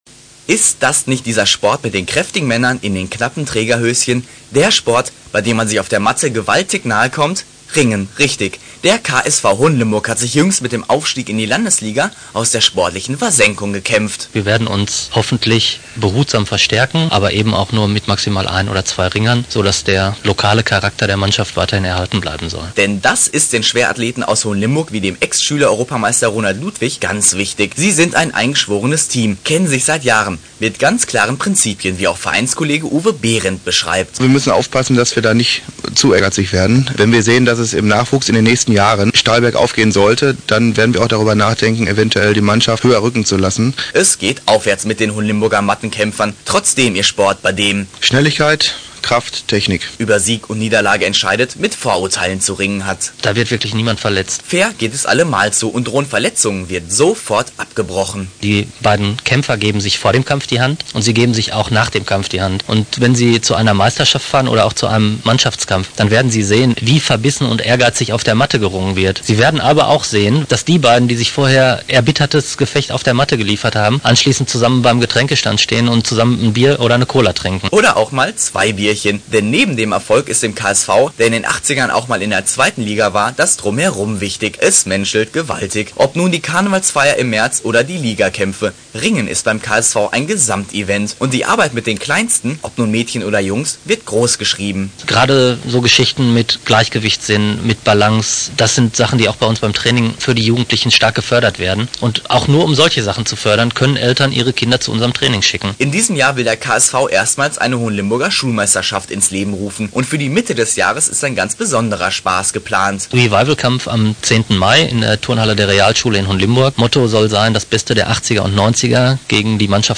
Reportage von Radio Hagen: Oberligakampf des KSV Hohenlimburg  vom 20.10.1990